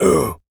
Male_Grunt_Hit_13.wav